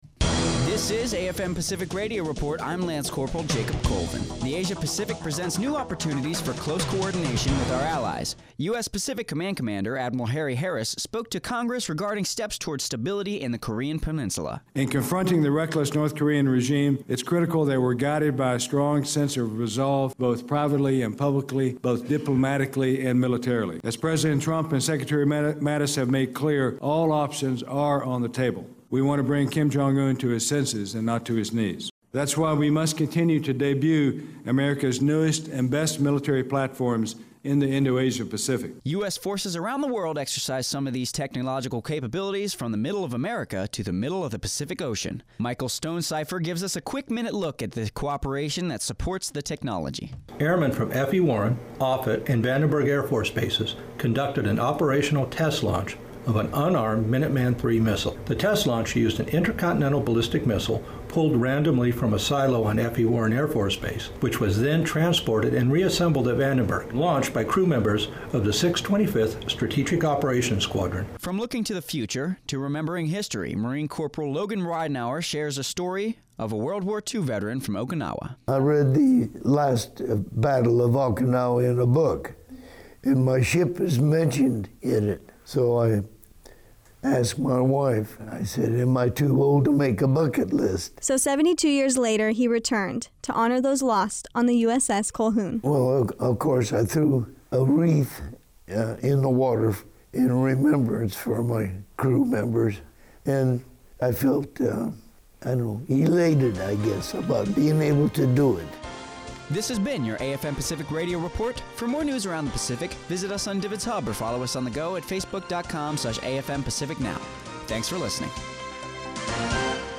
On this Radio report, Admiral Harry Harris addresses North Korea, Vanderberg Air Base tests missiles, and a World War II veteran shares his story.